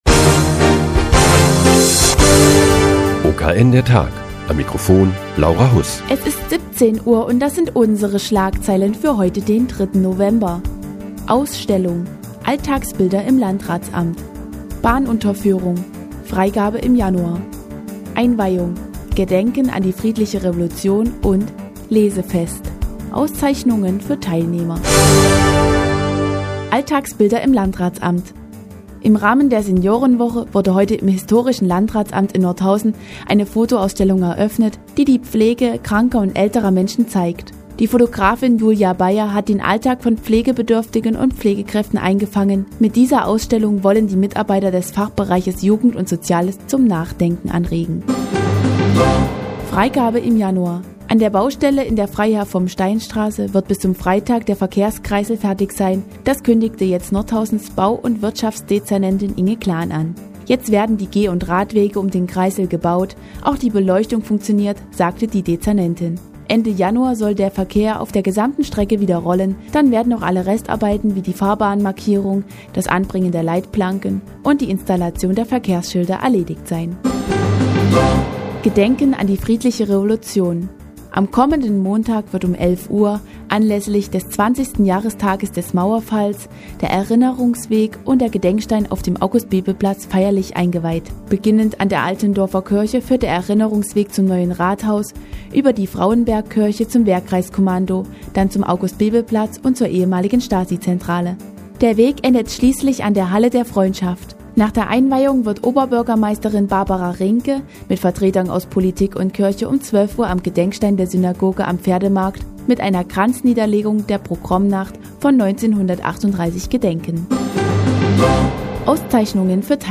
Die tägliche Nachrichtensendung des OKN ist nun auch in der nnz zu hören. Heute geht es um die Einweihung des Erinnerungsweges und ein Lesefest im Tabakspeicher.